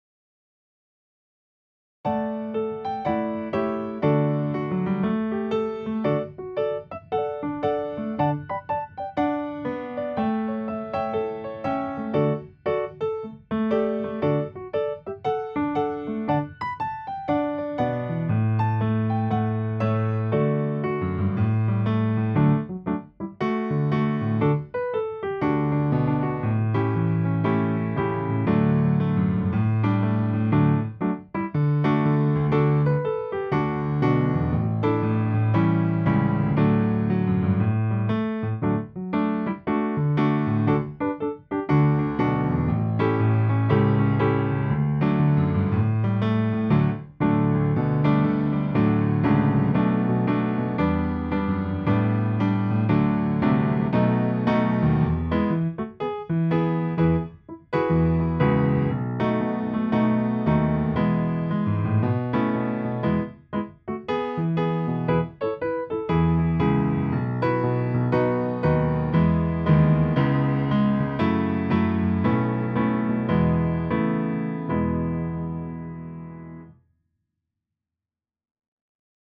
Backing -